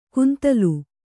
♪ kuntalu